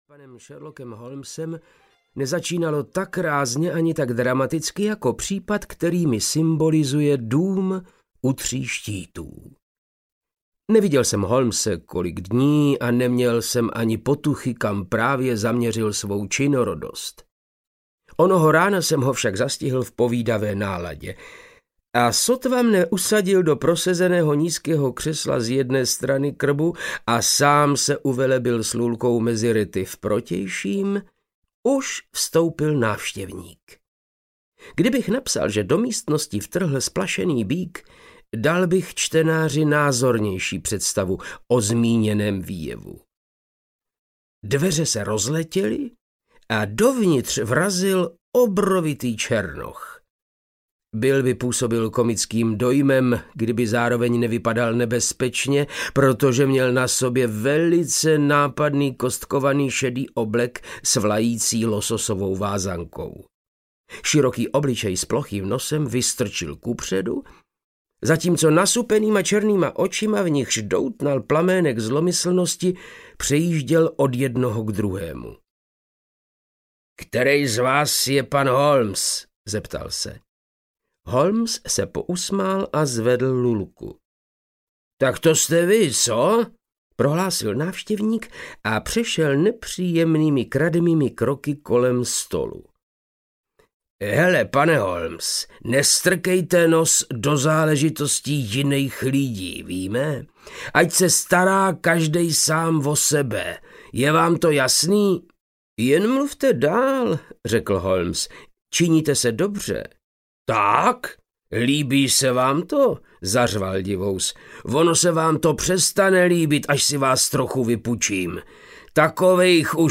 Dům U tří štítů audiokniha
Ukázka z knihy
• InterpretVáclav Knop